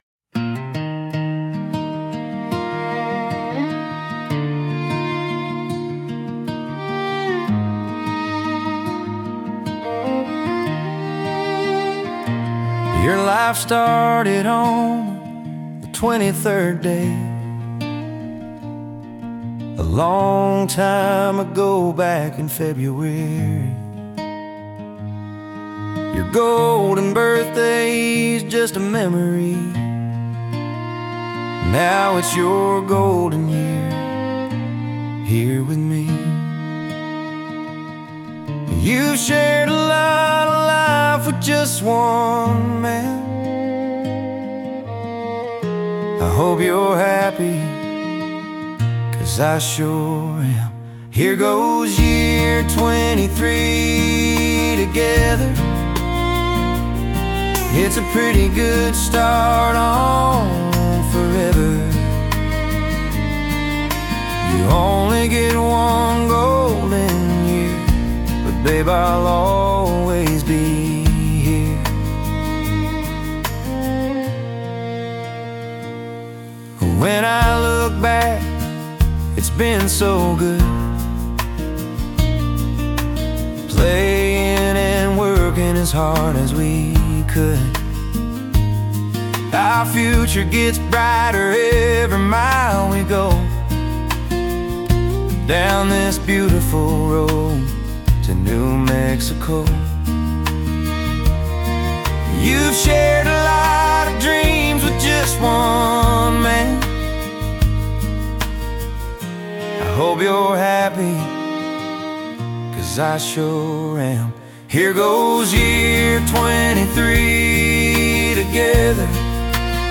I wrote the words and AI helped me with the music.)